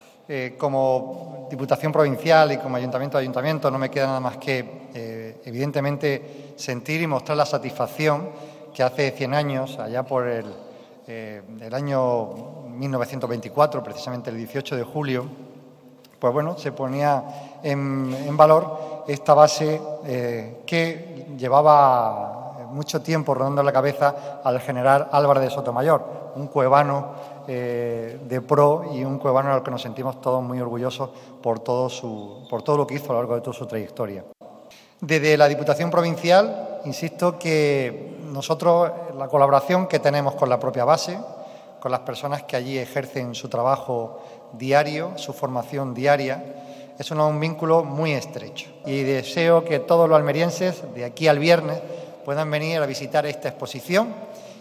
14-10_expo_base_viator__pdte._dipu.mp3